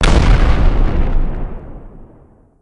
Explosion2.ogg